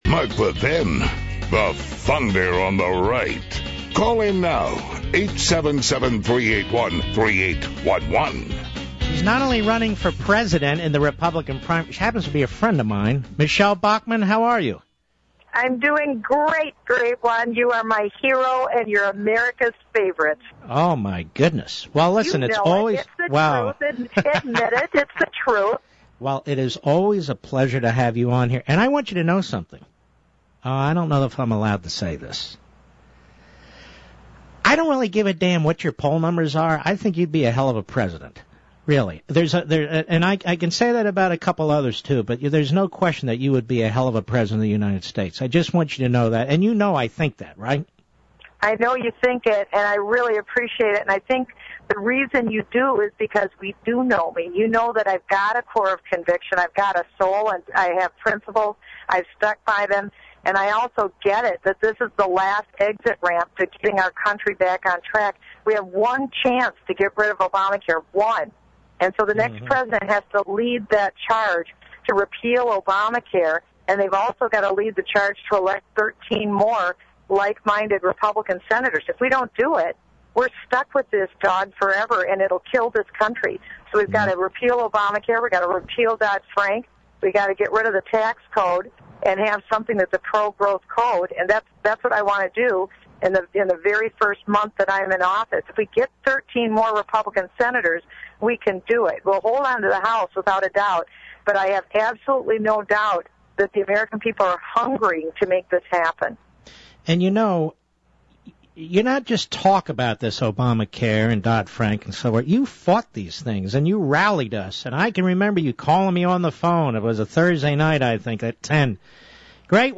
In an interview Michele Bachmann had recently on Mark Levin’s radio show, she explains the entire story.
Click here to hear Mark Levin interview Michele Bachmann . Where she explains what NBC News did starts at the 7:20 mark.